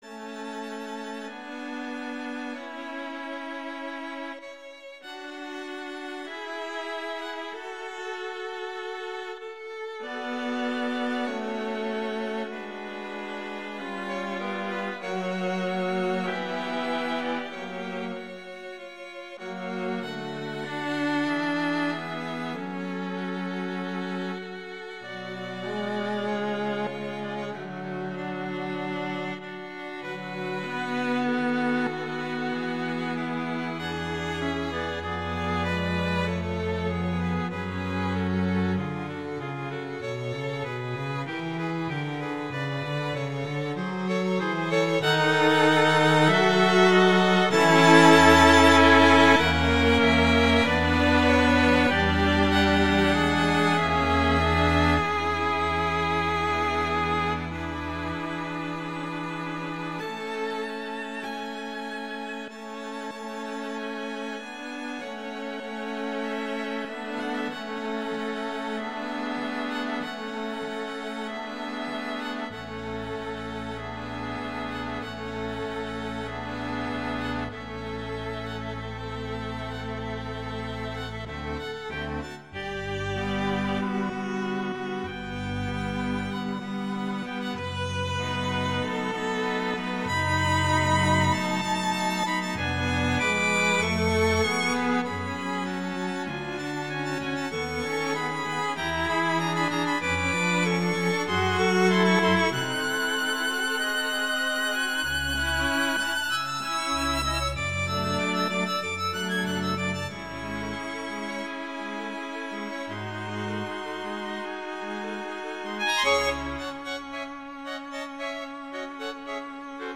classical
A major
♩=48 BPM